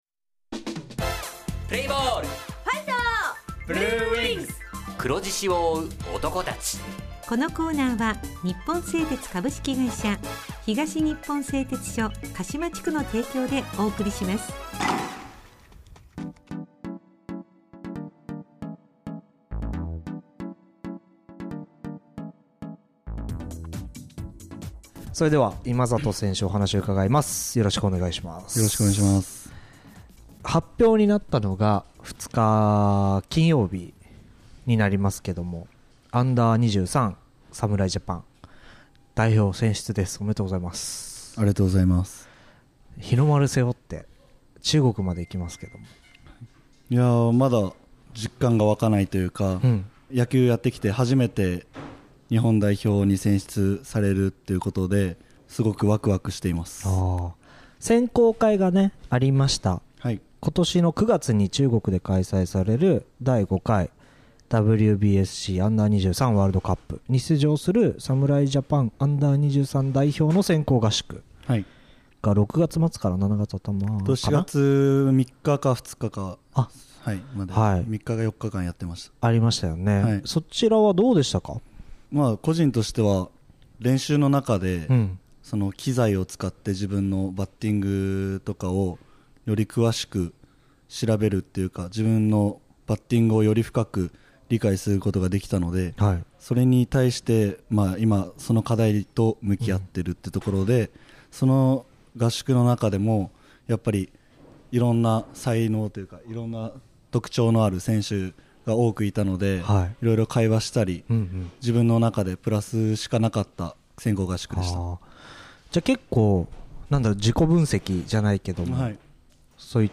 選手インタビュー
地元ＦＭ放送局「エフエムかしま」にて鹿島硬式野球部の番組放送しています。